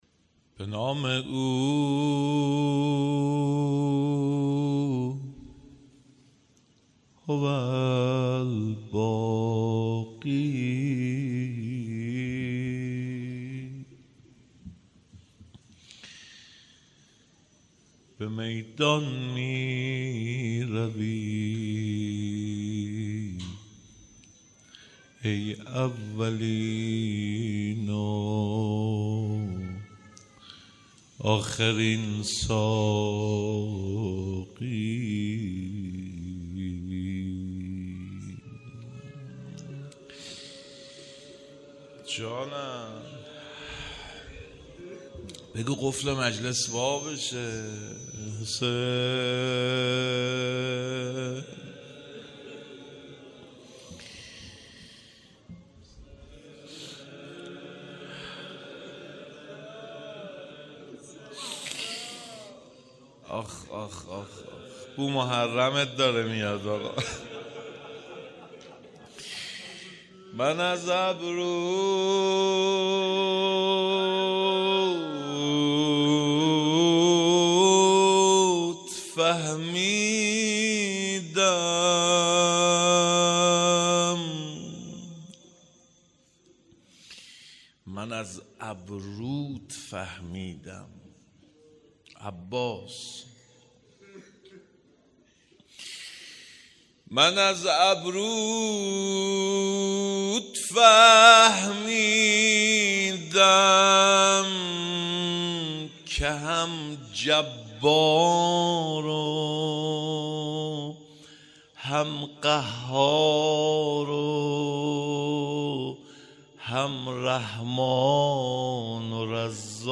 روضه خوانی
شب اول مراسم شهادت امام جوادعلیه السلام